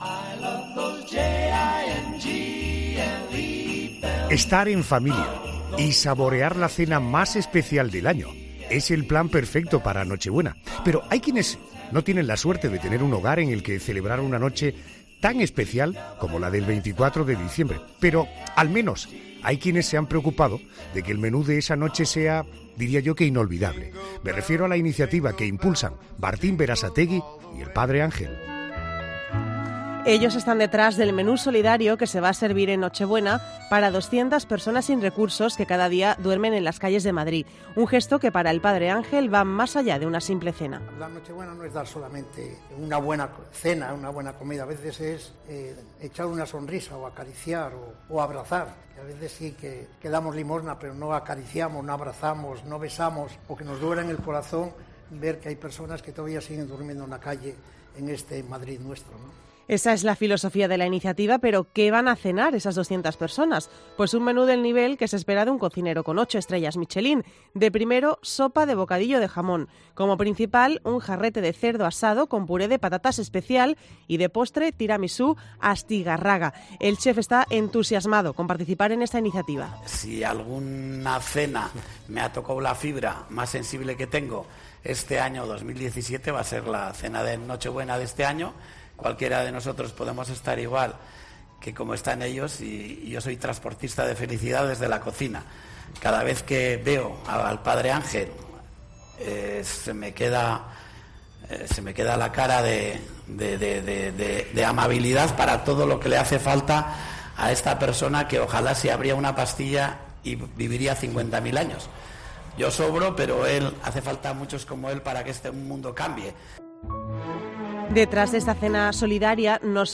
El chef ocho estrellas Michelín ha pasado por 'La Noche de COPE'